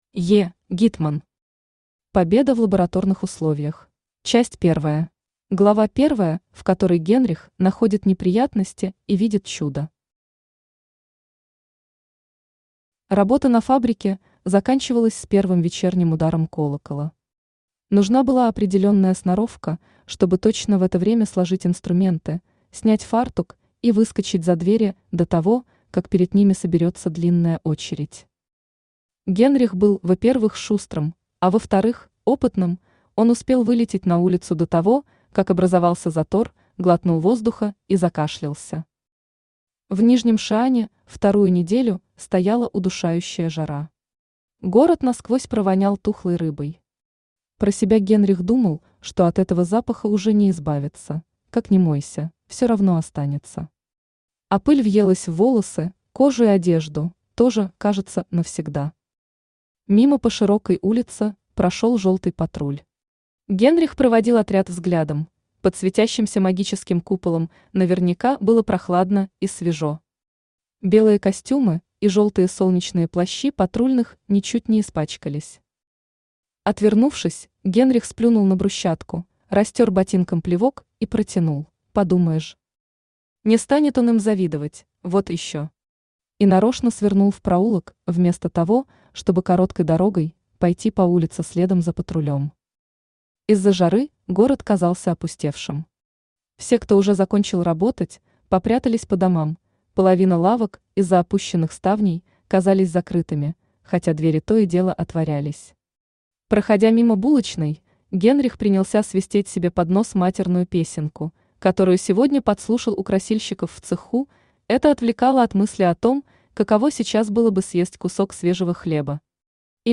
Аудиокнига Победа в лабораторных условиях | Библиотека аудиокниг
Aудиокнига Победа в лабораторных условиях Автор Е. Гитман Читает аудиокнигу Авточтец ЛитРес.